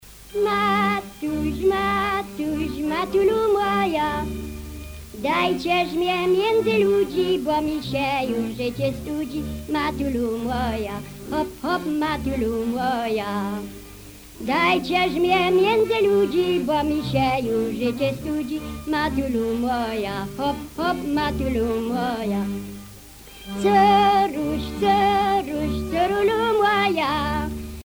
Enquête Lacito-CNRS
Pièce musicale inédite